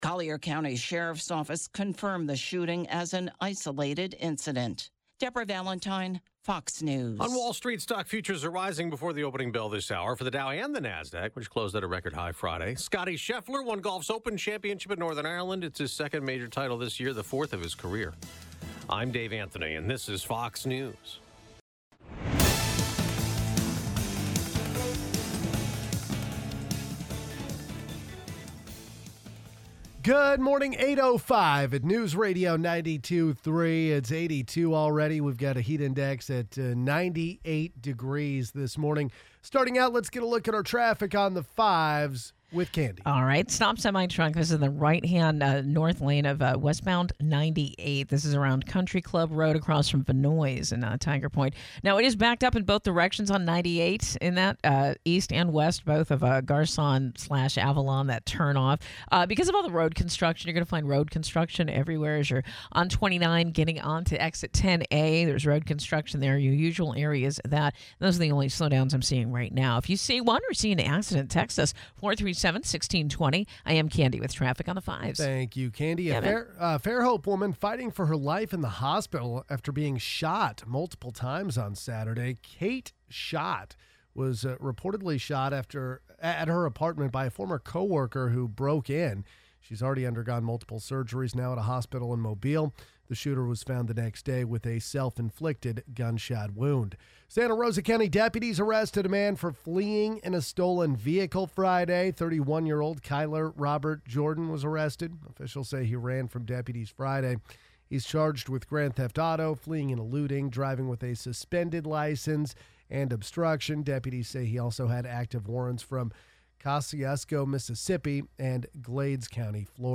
Interview w Bob Johnson, Sheriff of Santa Rosa County talks: